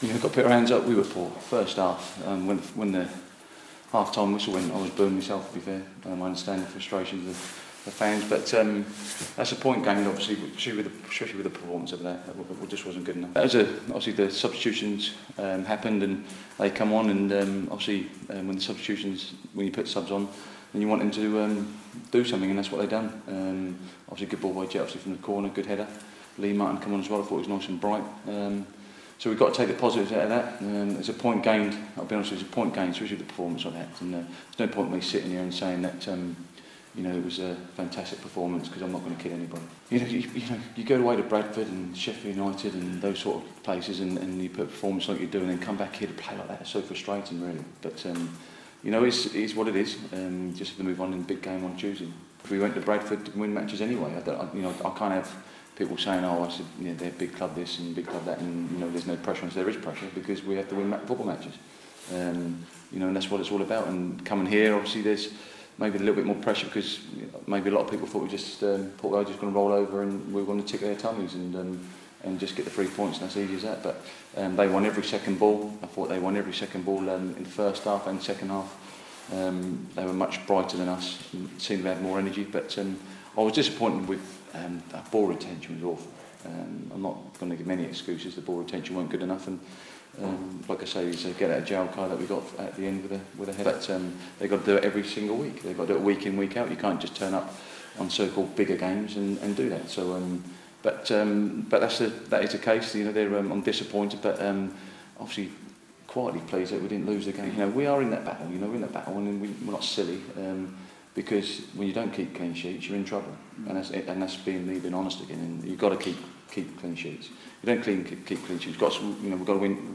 speaking after 1-1 draw against Port Vale